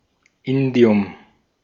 Ääntäminen
Ääntäminen Tuntematon aksentti: IPA: /ˈɪndi̯ʊm/ Haettu sana löytyi näillä lähdekielillä: saksa Käännös 1. indio {m} Artikkeli: das .